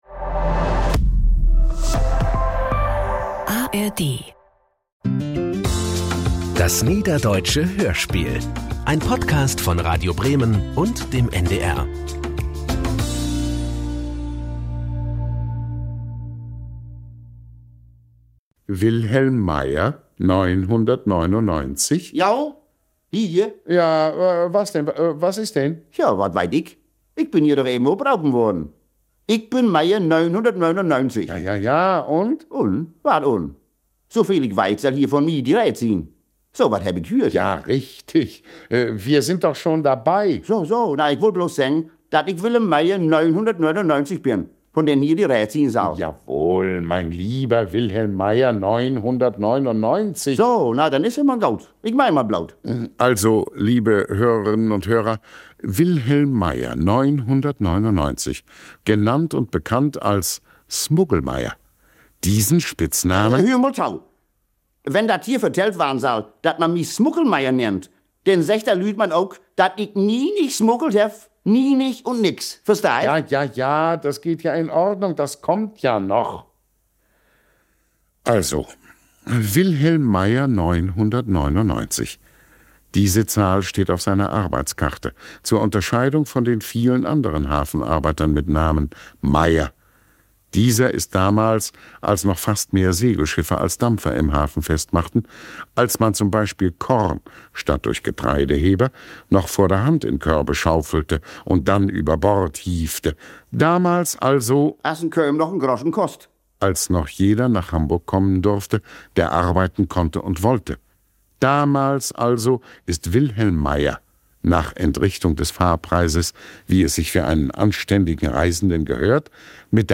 Niederdeutsches Hörspiel
… continue reading 147 episodes # Hörspiele # Geschichtenerzählen # Audio Drama # Wahre Geschichten # Bildung # Thu Oct 29 10:00:03 CET 2020 Radio Bremen # Radio Bremen